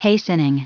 Prononciation du mot hastening en anglais (fichier audio)
Prononciation du mot : hastening